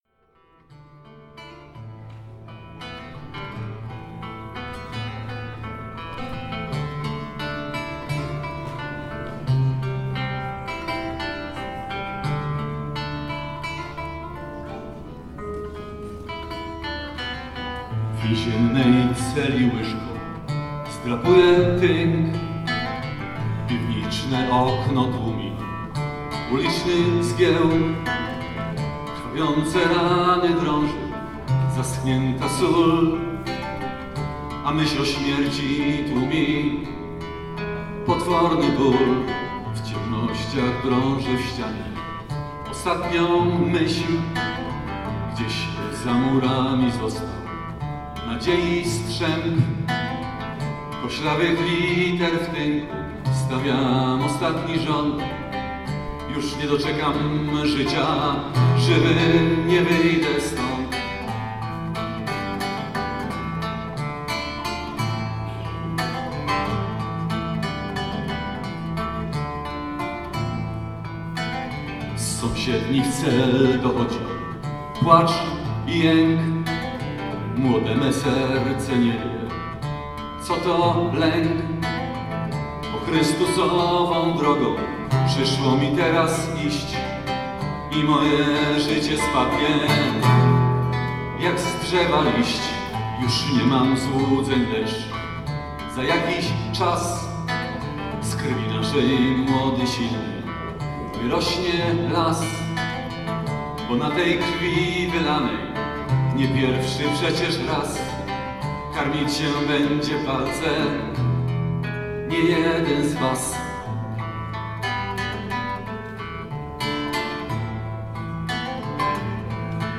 Zapraszamy do wys�uchania obszernego fragmentu jego wyst�pienia.